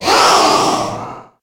Cri de Roublenard dans Pokémon HOME.